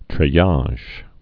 (trĕ-yäzh, trālĭj)